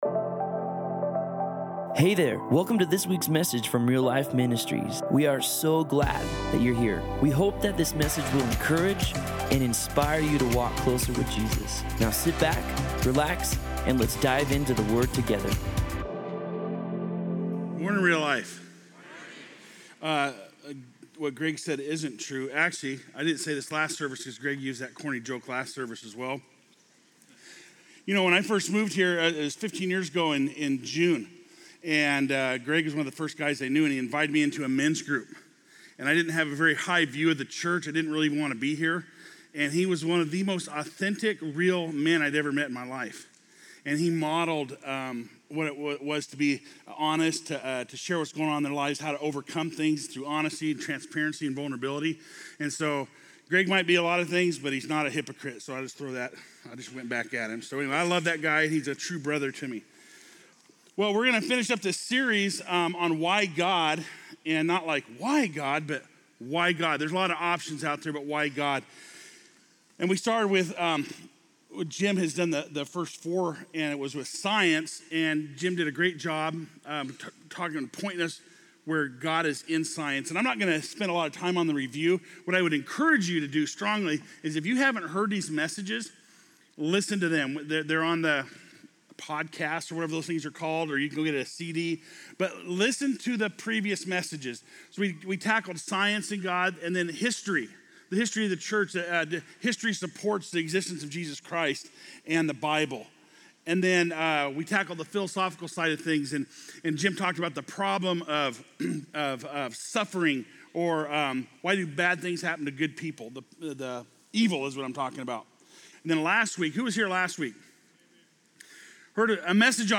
Post Falls Campus